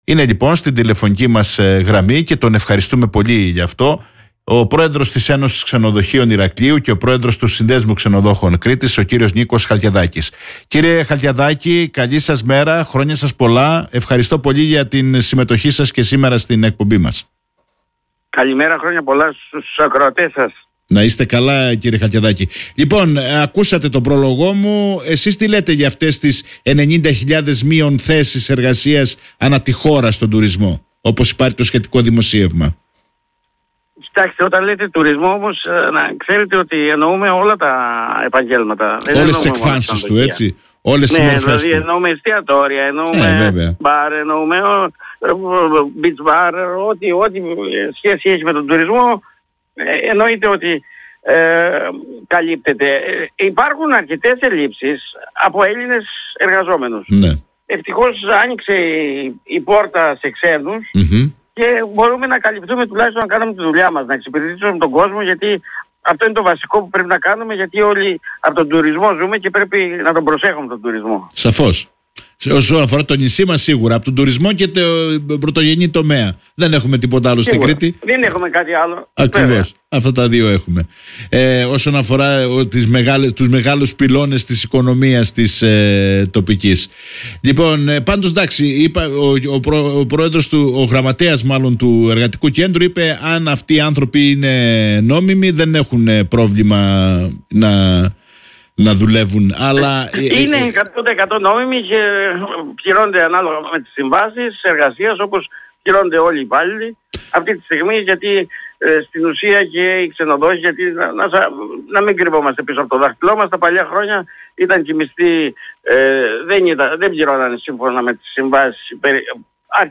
μιλάει στην εκπομπή “Όμορφη Μέρα”